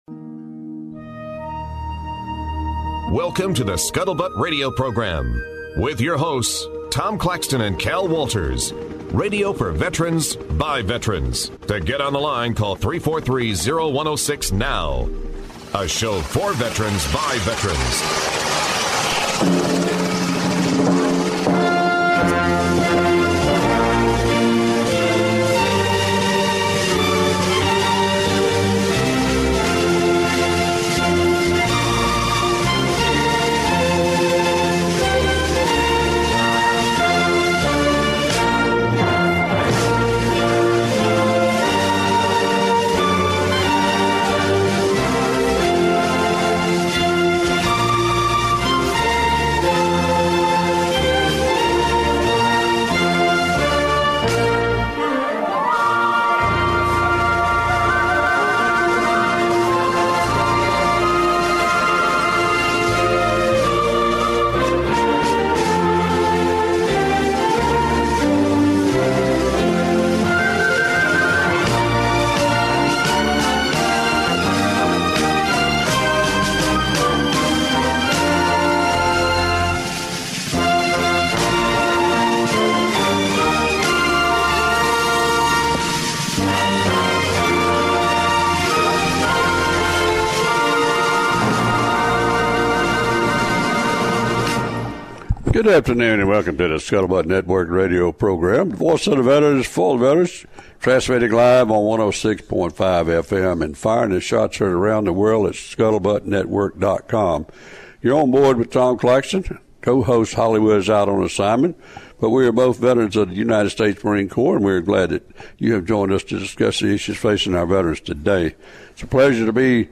A show for Veterans ... from Veterans . Live from the USS Alabama Battleship at Memorial Park in Mobile, Alabama